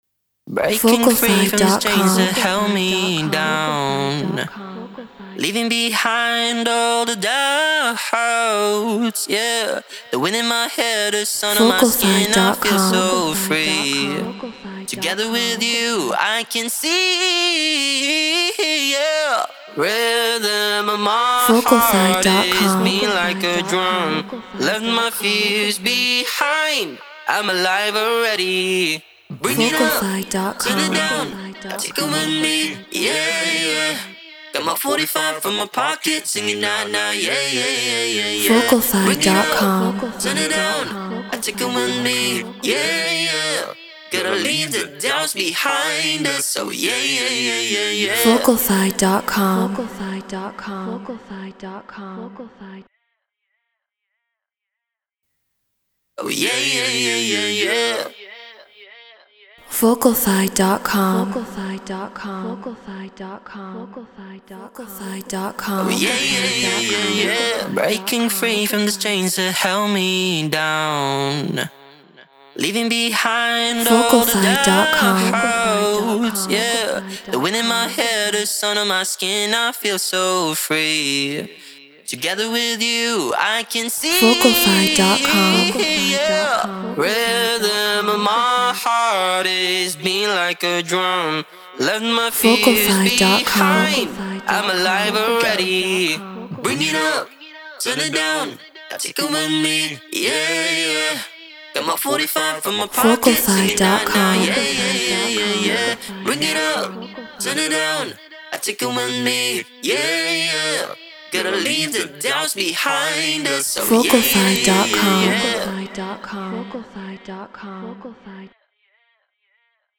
Non-Exclusive Vocal.
EDM 100 BPM D#min
Studio Mic Treated Room